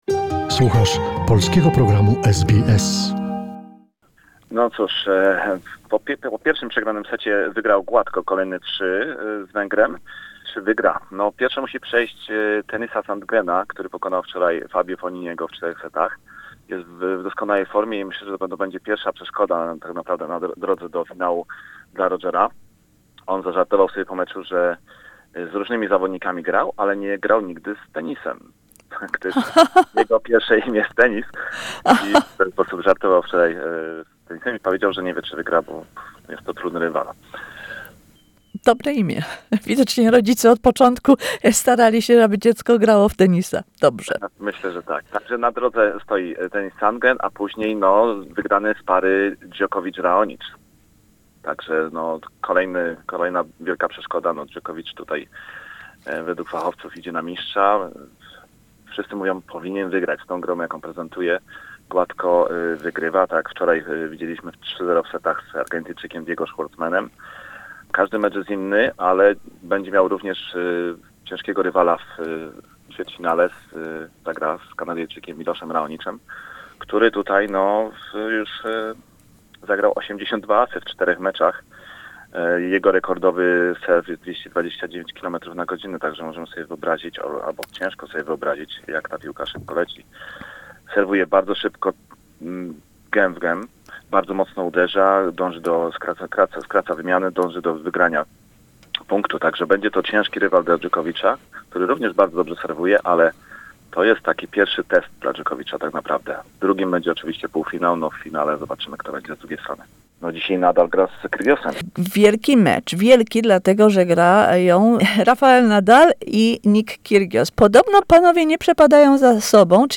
Czego można oczekiwać po meczu dwóch nielubiących się rywali Rafaela Nadala z Nickiem Kyrgiosem. Relacja